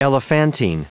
Prononciation du mot elephantine en anglais (fichier audio)
Prononciation du mot : elephantine